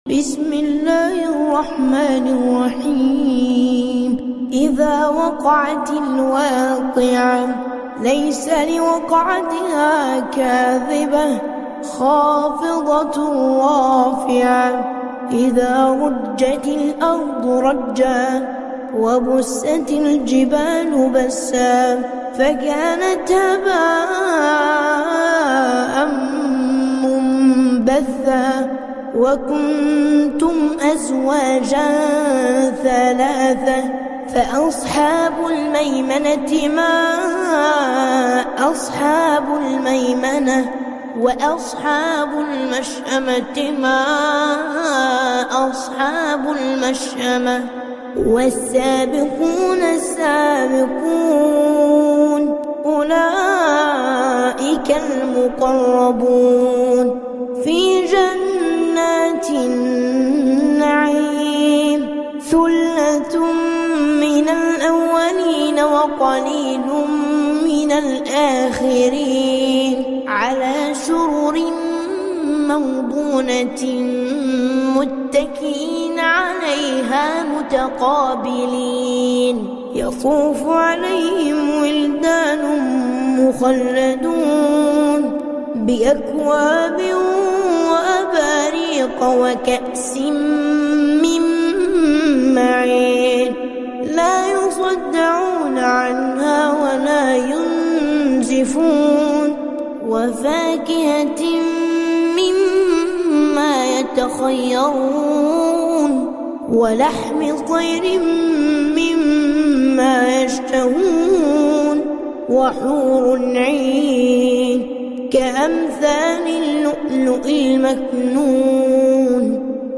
Quran Recitation